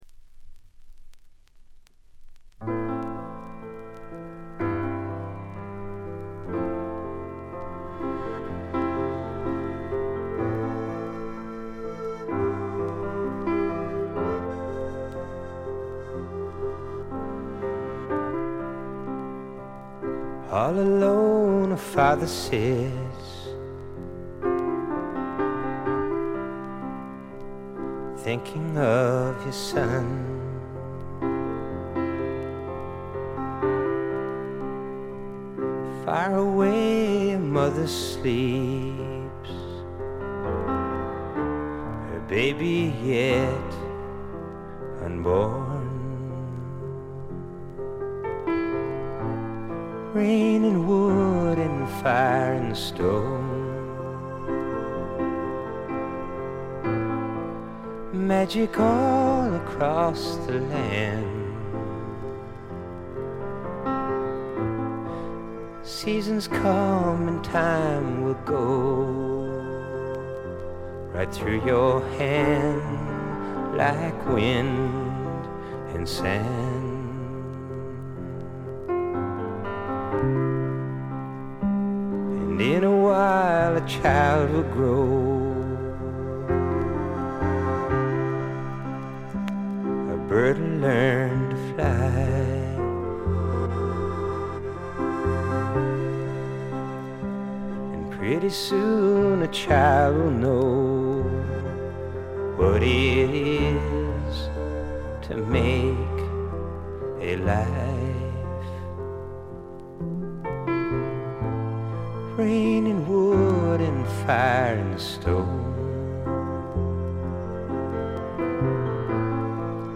部分試聴ですが、軽いチリプチ、散発的なプツ音少し。
控えめな演奏をバックに複雑な心象風景を淡々と描いていく歌声が本作の最大の魅力でしょう。
試聴曲は現品からの取り込み音源です。